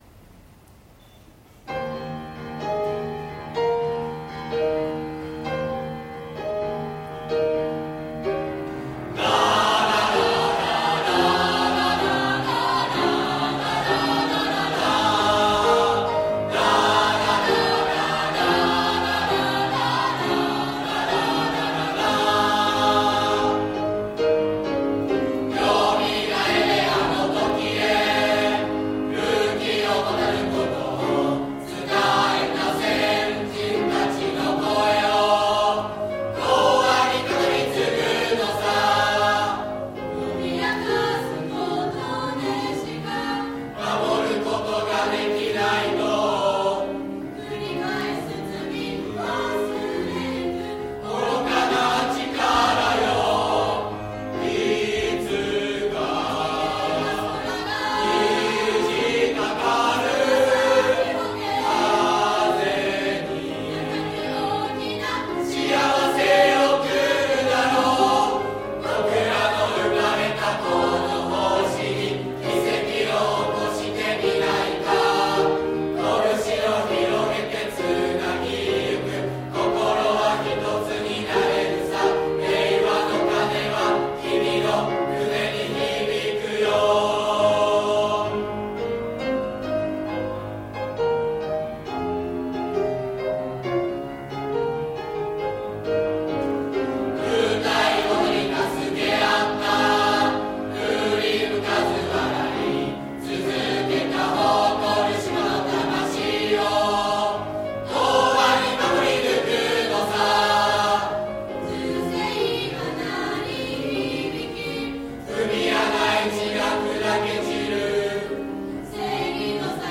3年2組の合唱コンクール
曲名をクリックすると、歌声が流れます。